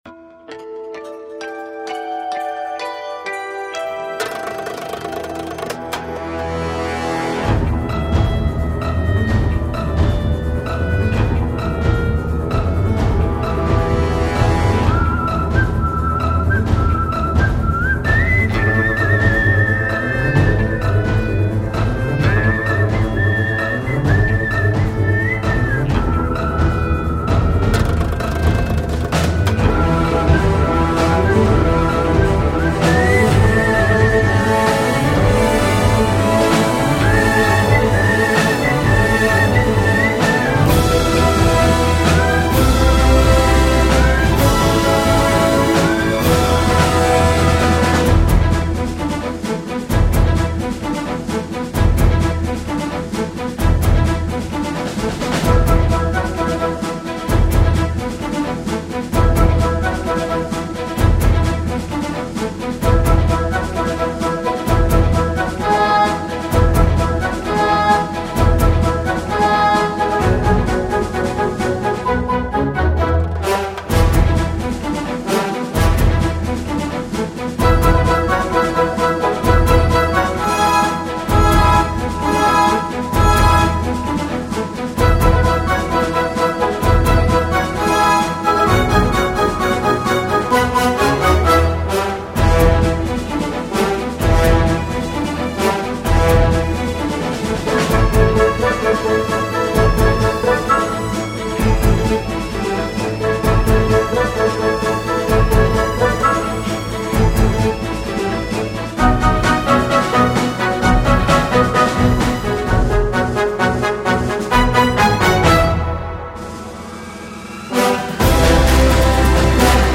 Free Download Instrumental